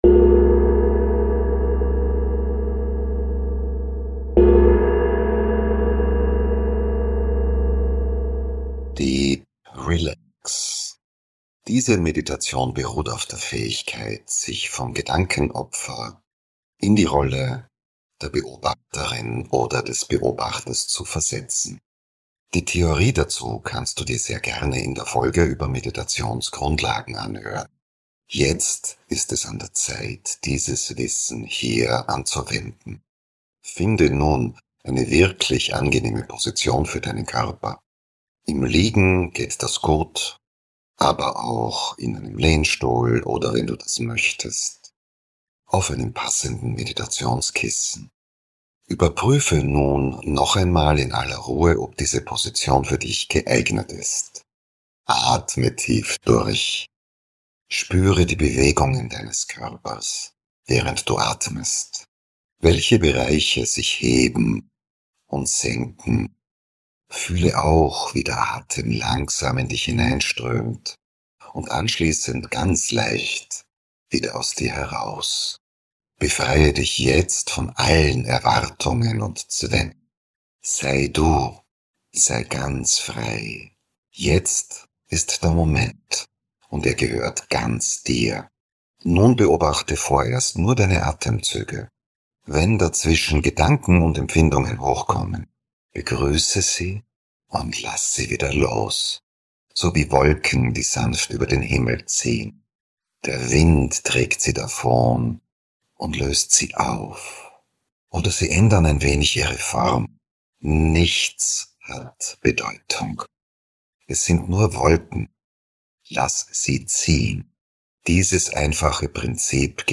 Mit sanfter Stimme, ruhigen
Frequenzen und achtsamen Impulsen begleitet sie dich in einen